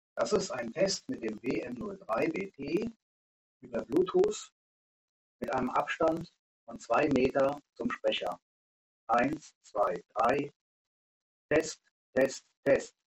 Beispiel: Bluetooth Verbindung (gleiches Gerät wie im nächsten Audiotest)